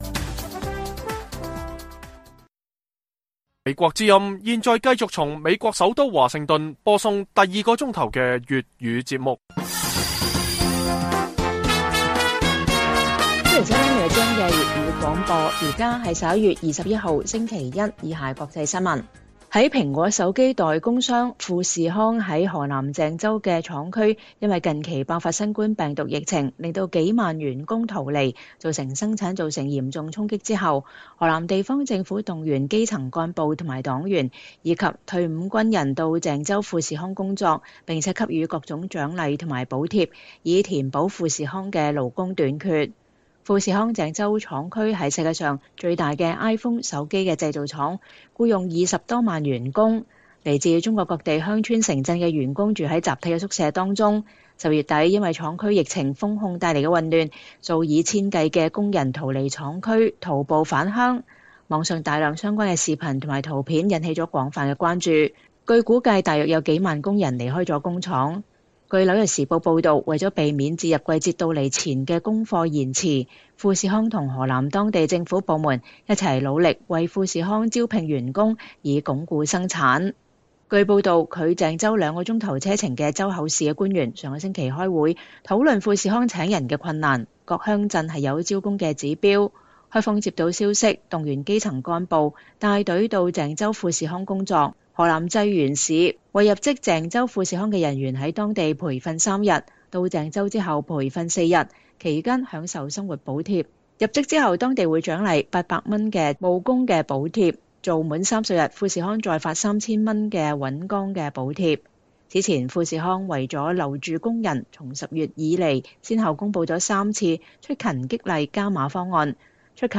粵語新聞 晚上10-11點: 河南動員黨員及退伍軍人入職富士康填補勞工短缺